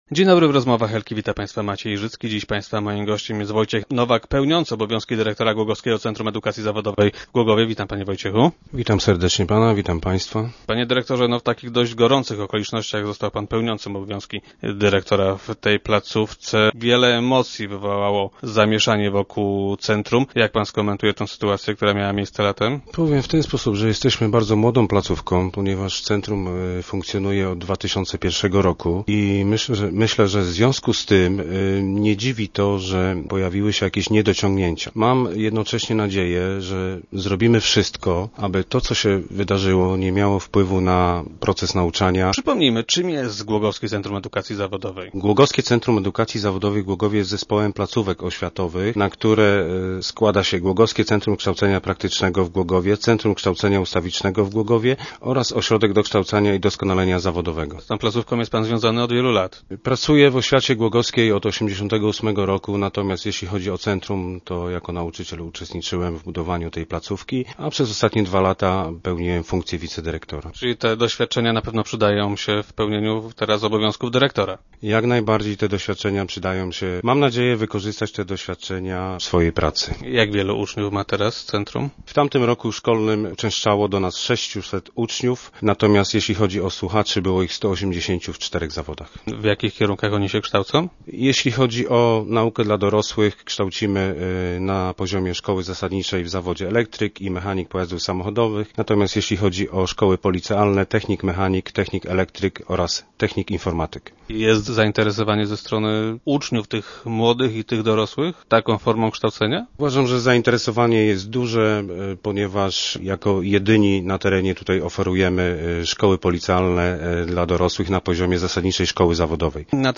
Dziś był on gościem Rozmów Elki. Jak powiedział placówka musi się rozwijać, a ostatnie zawirowania nie powinny w tym przeszkodzić.